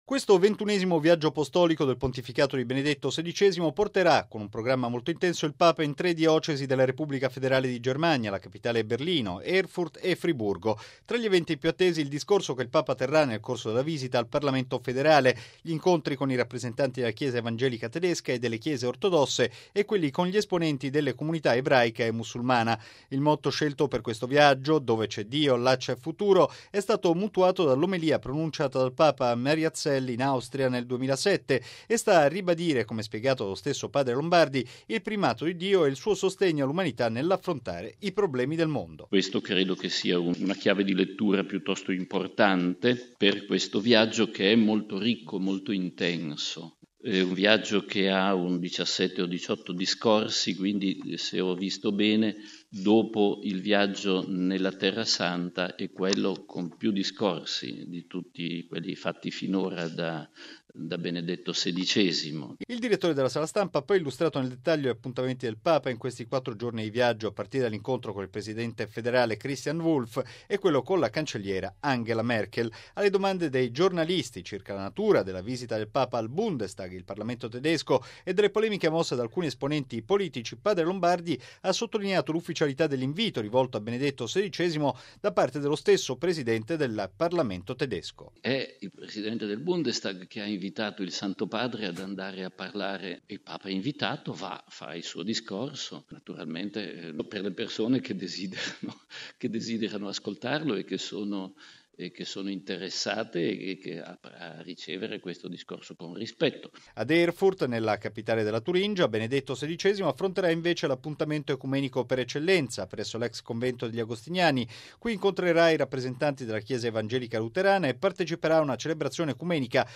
◊   Il direttore della Sala Stampa della Santa Sede, padre Federico Lombardi, ha illustrato stamattina ai giornalisti il programma del viaggio apostolico di Benedetto XVI in Germania, che si svolgerà dal 22 al 25 settembre prossimi. Si tratta del terzo viaggio del Papa nella sua terra natale dopo quello compiuto nell’agosto del 2005 per la XX Giornata Mondiale della Gioventù a Colonia e quello svoltosi nel settembre 2006 in Baviera.